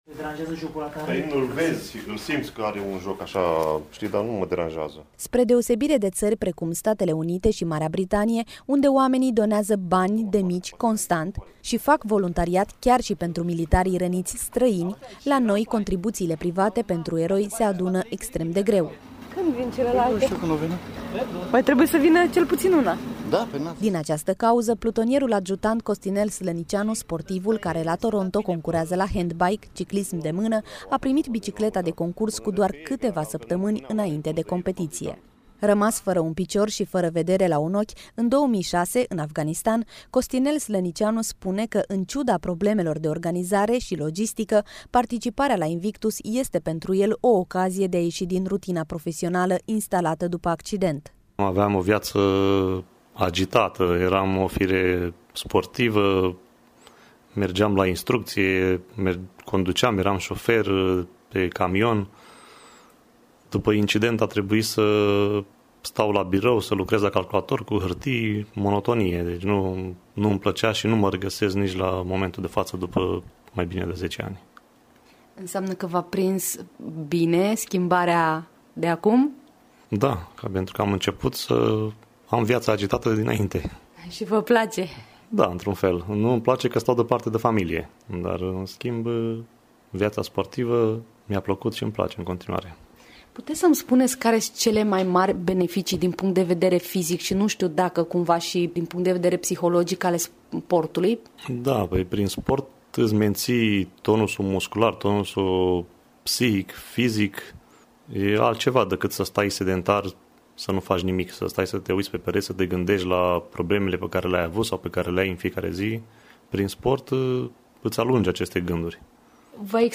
reportaj și interviuri